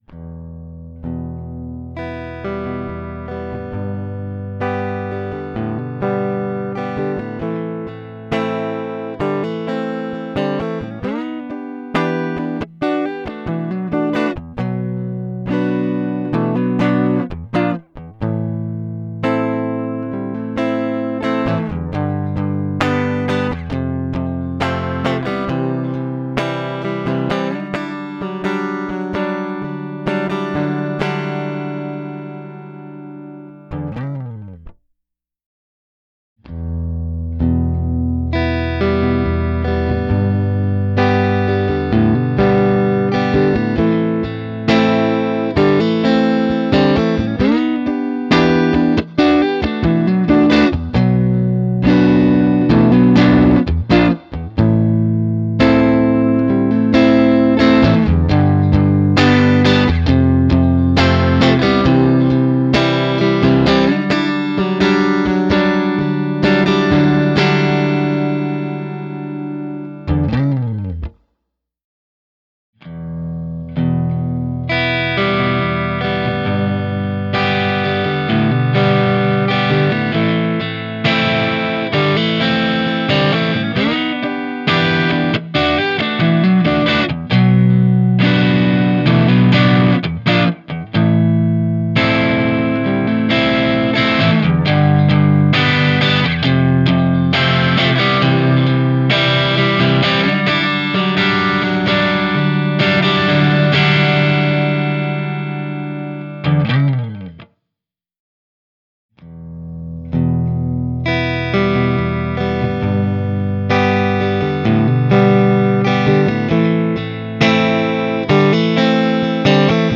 amp sim demo raw tweed orange ac30 plexi jc120.mp3